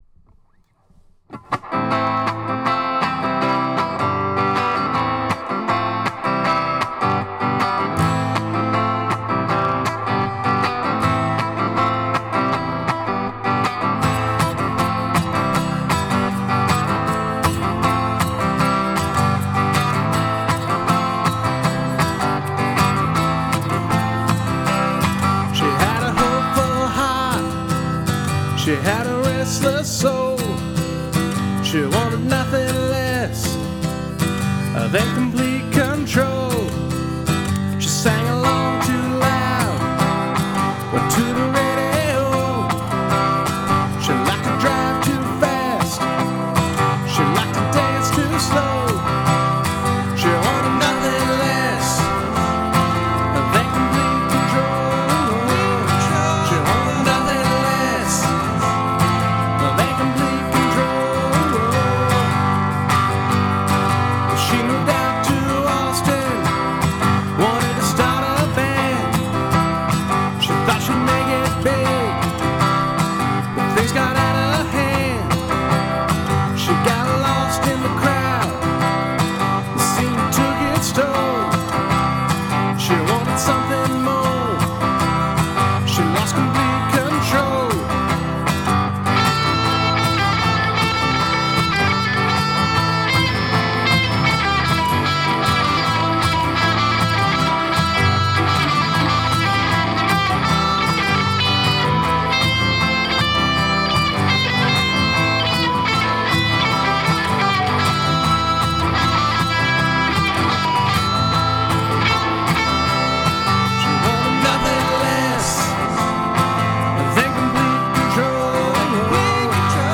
This first song is one I wrote and recorded myself (with the exception of the guitar solo, which was conceived and played by one of my students at school). I’m pretty damn pleased with how the song turned out; all I really need is some drums (and possibly the ability to play said drums.